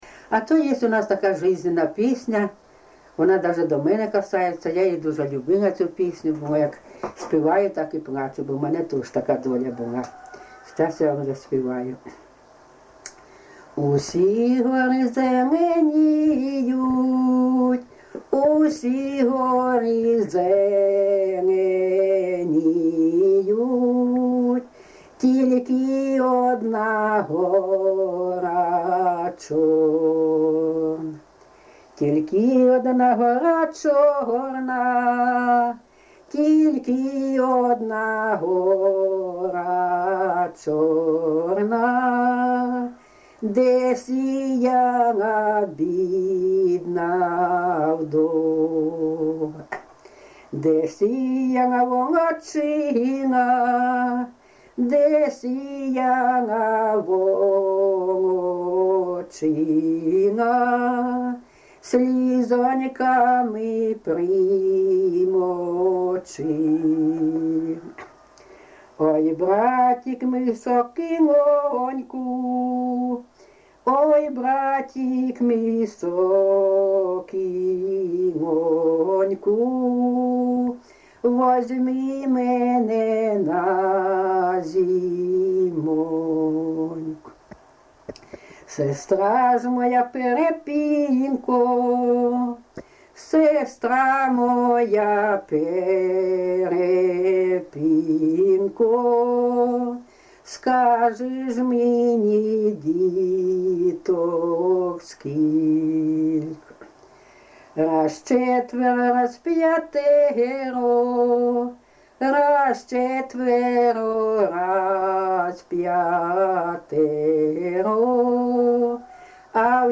ЖанрПісні з особистого та родинного життя, Балади
Місце записус. Курахівка, Покровський район, Донецька обл., Україна, Слобожанщина